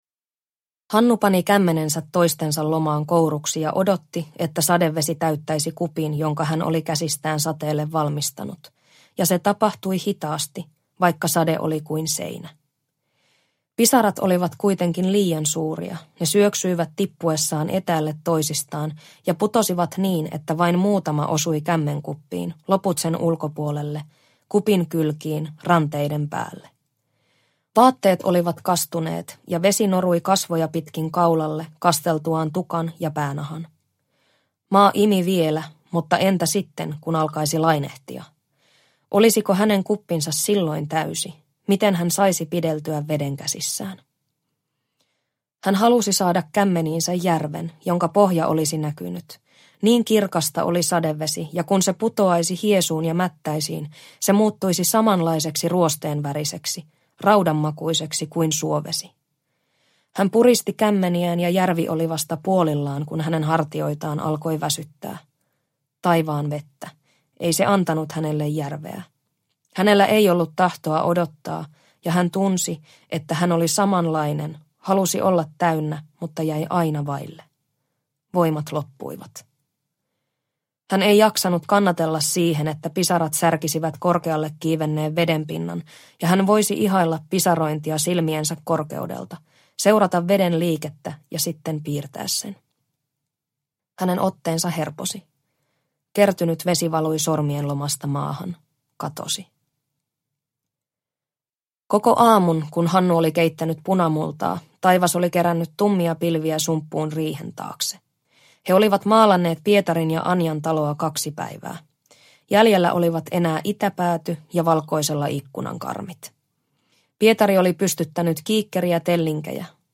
Suopursu – Ljudbok – Laddas ner